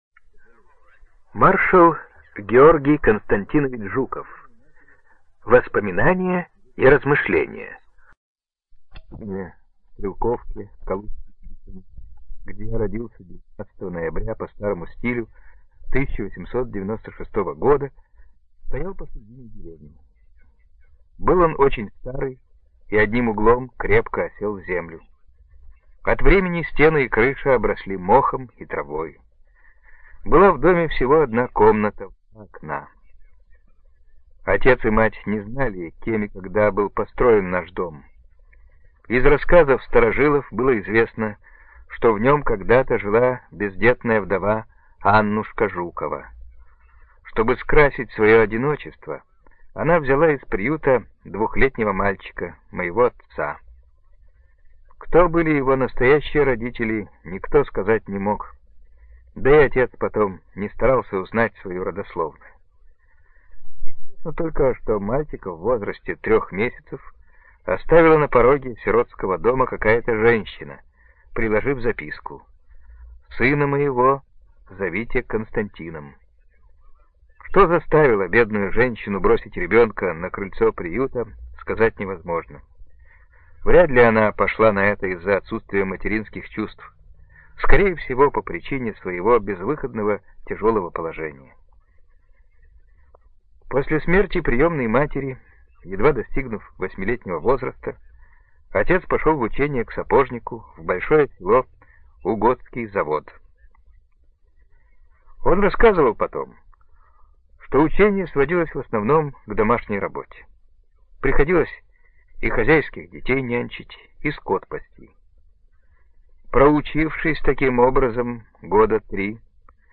ЖанрБиографии и мемуары
Студия звукозаписиРеспубликанский дом звукозаписи и печати УТОС